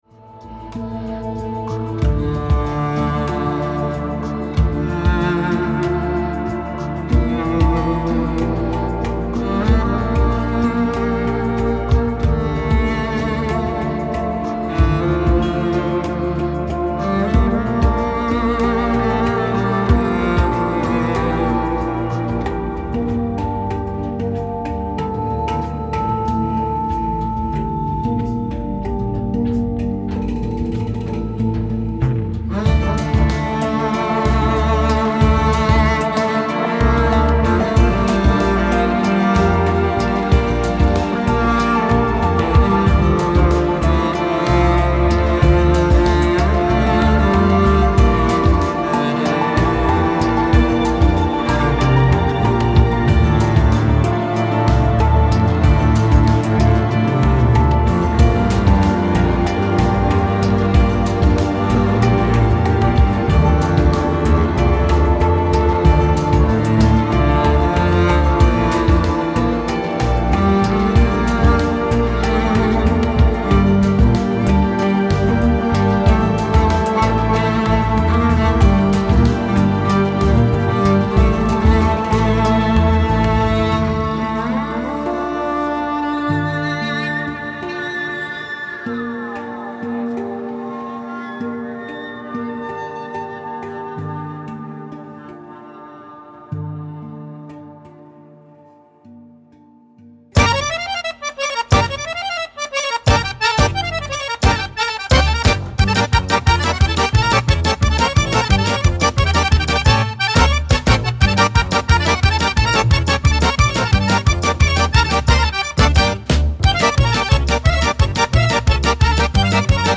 Percussion/Xylophon
Akkordeon
Kontrabass/Gitarre
Violine/Gesang
Gesang
Audio-Mix